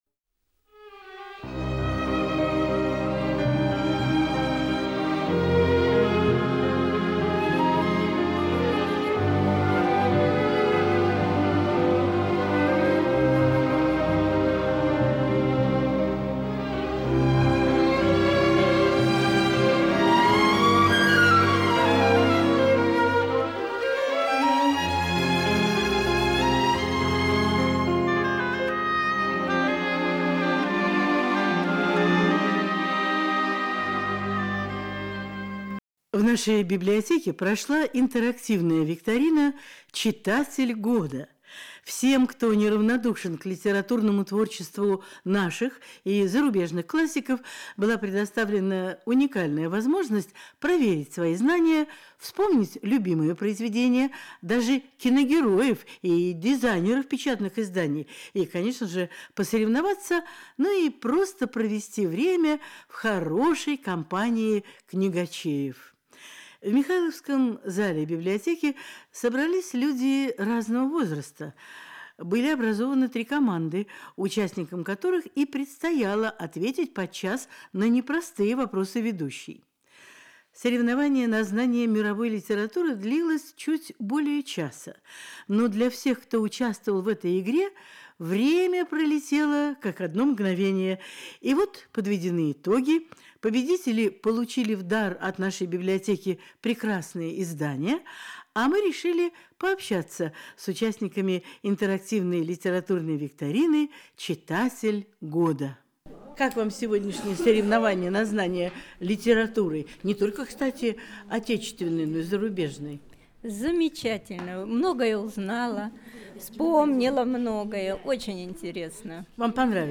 «Читатель года» интервью с участниками интерактивной викторины по литературе